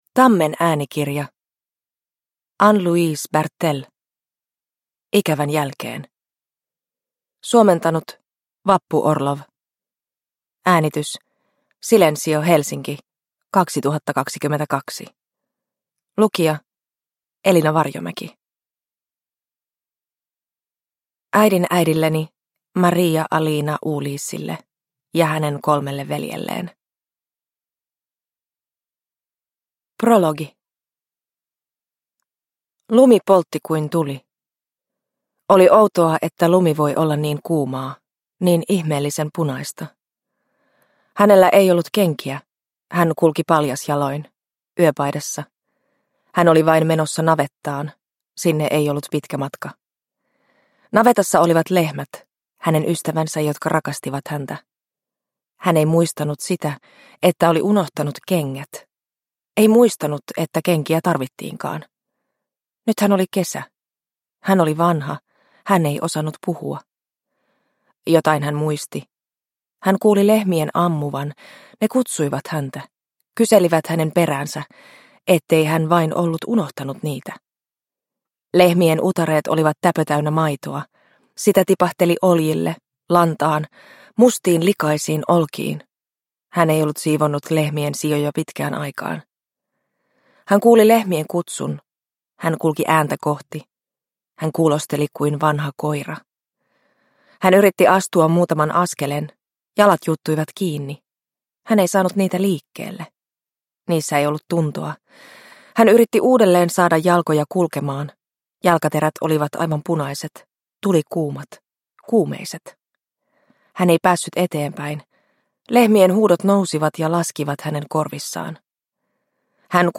Ikävän jälkeen – Ljudbok – Laddas ner